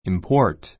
import impɔ́ː r t イン ポ ー ト 動詞 輸入する import coffee from Brazil import coffee from Brazil ブラジルからコーヒーを輸入する Our country imports oil and exports products made from oil.